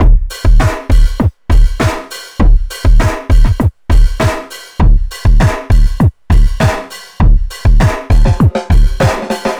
funkis 100bpm 04.wav